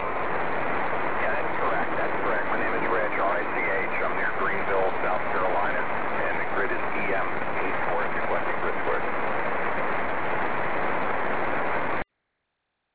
INFO: I listen today, at 1520z an USA station sited in S. Carolina in EM84